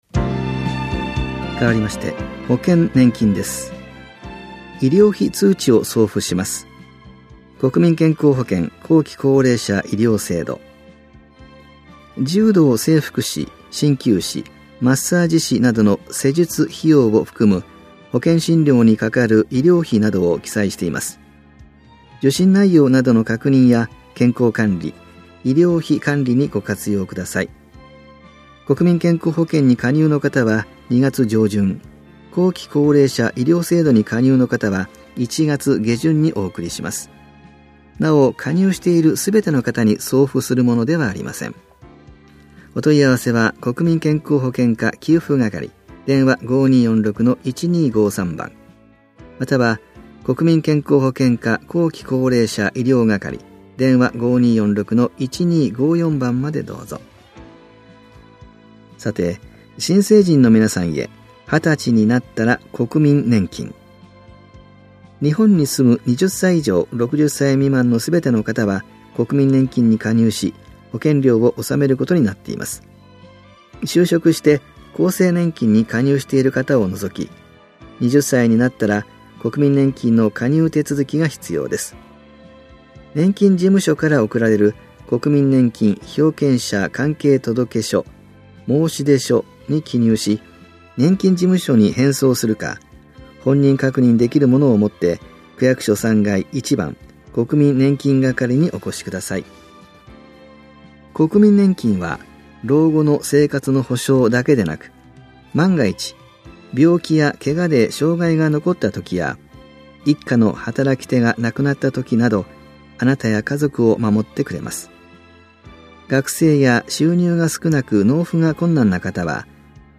広報「たいとう」平成31年1月20日号の音声読み上げデータです。